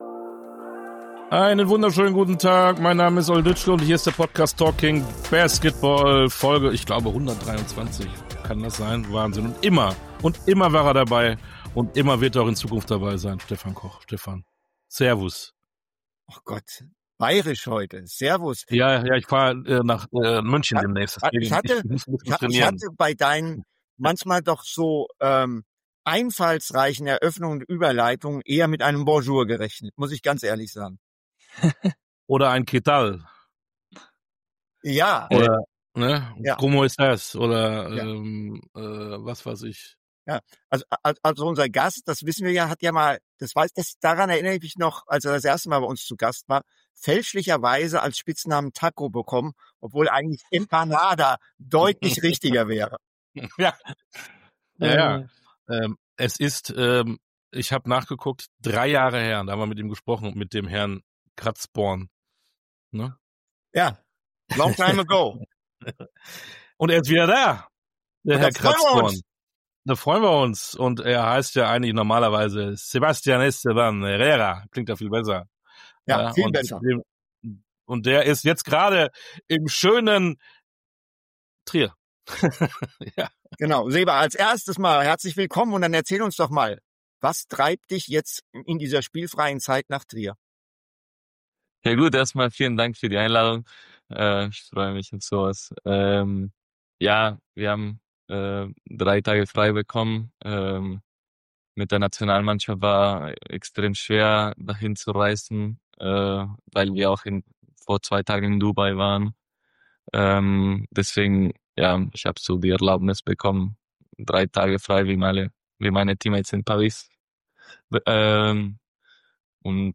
Interviews in voller Länge Podcast